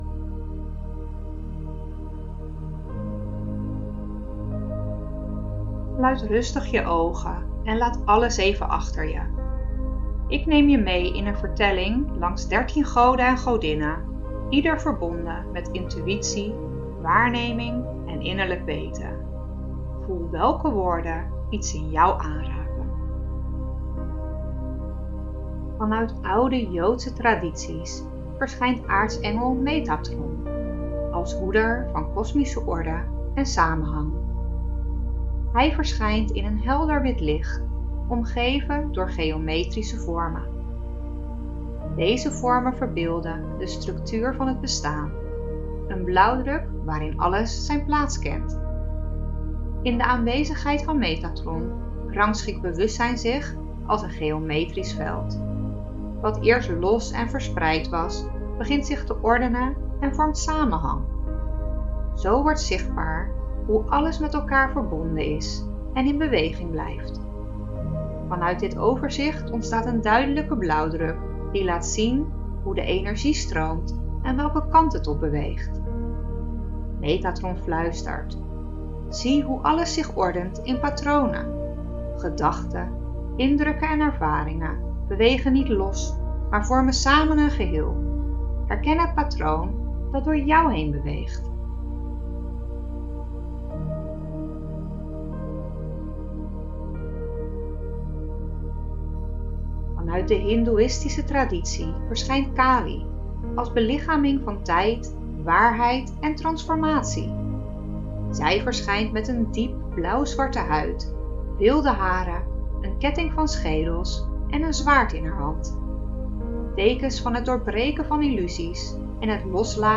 vertelling goden intuitie web.mp3